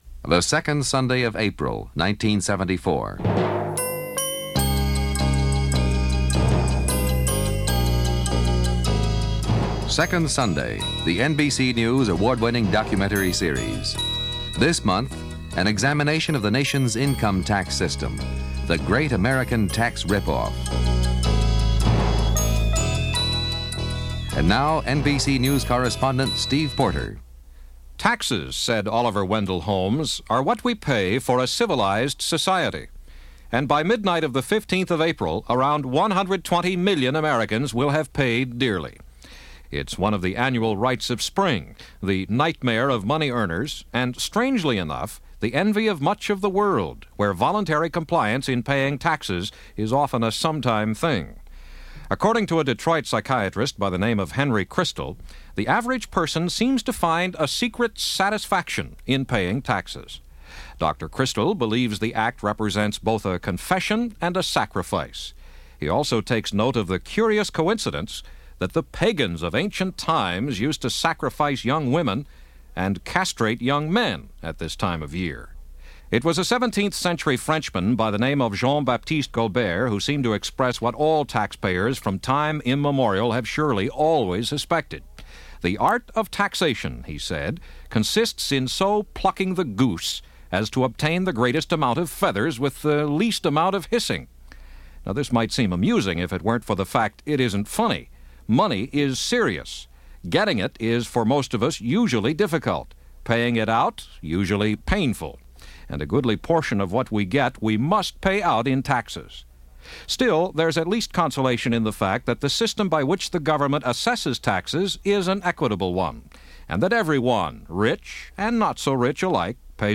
The That's Got - Taxes In America -1974 - Past Daily Reference Room - Radio documentary from 1974 on the tax system in America.